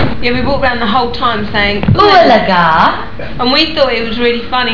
(all files here are in *.wav format. Recorded at 11025 Hz in 8 bit mono)